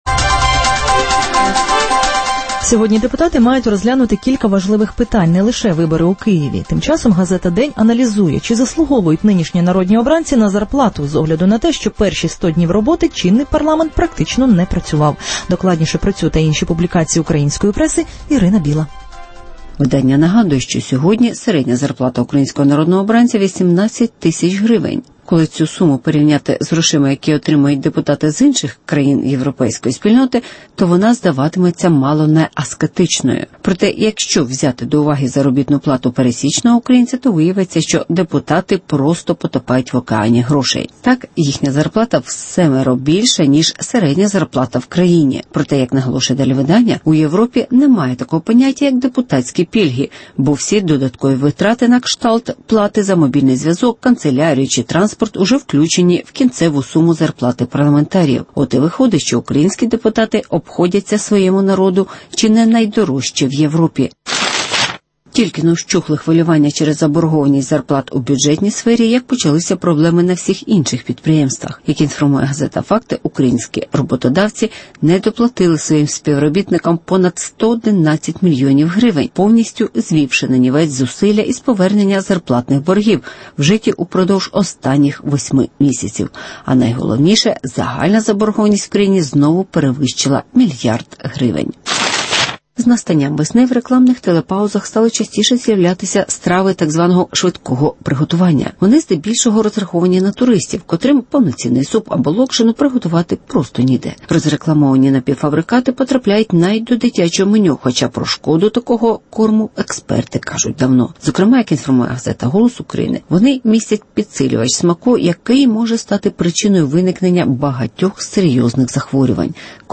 Влада хоче приборкати парламент референдумом (огляд преси)